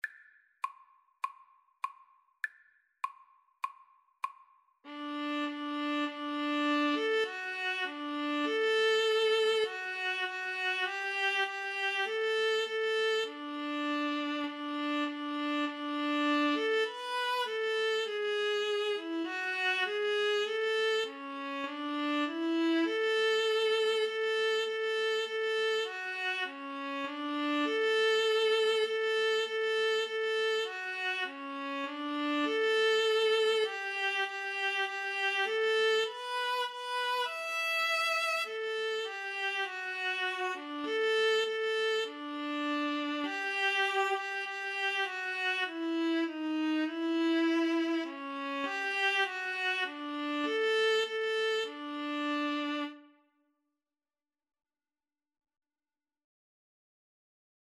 Free Sheet music for Violin-Viola Duet
D major (Sounding Pitch) (View more D major Music for Violin-Viola Duet )
Classical (View more Classical Violin-Viola Duet Music)